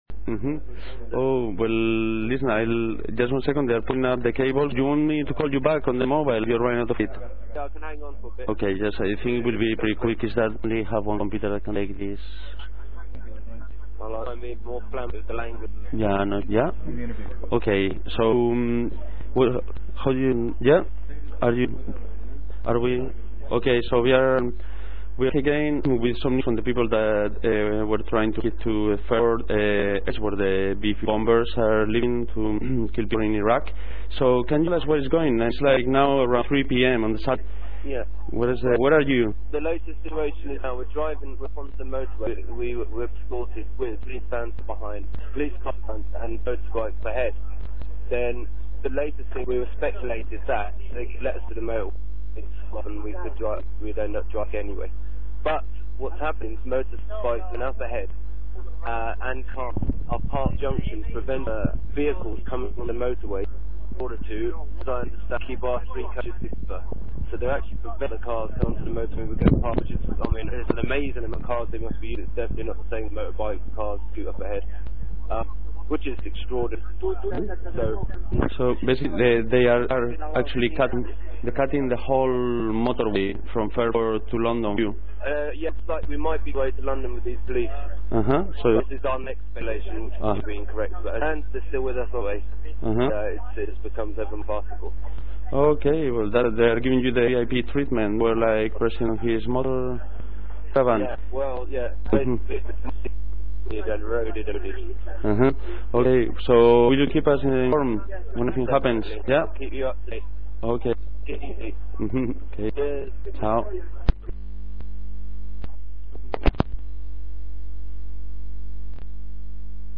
audio interviews from fairford demonstators
2 audio interviews from Fairford demonstrators conducted via cellphone. The protest was prevented from reaching the B-52 bomber base by police using Section 60 powers of preventative search and the demonstrators' coaches are now being escorted back to London by police who have closed motorway access around the coaches.